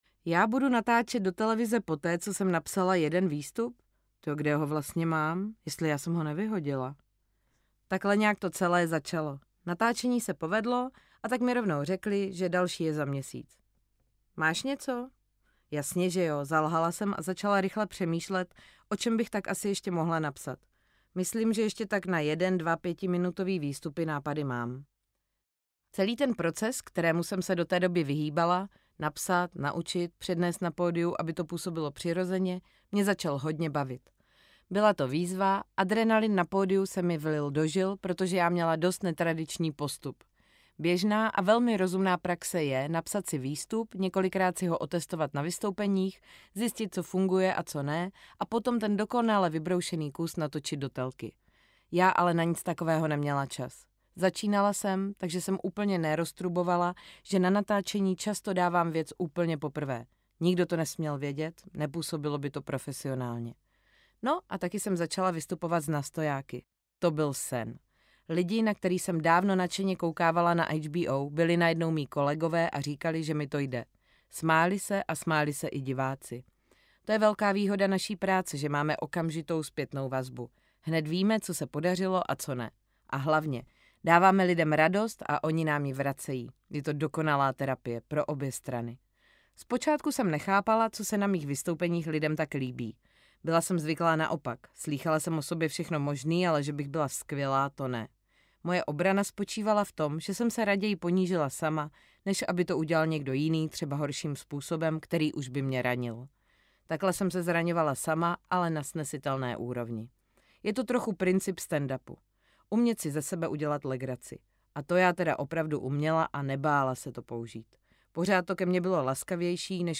Audiobook
MP3 Audiobook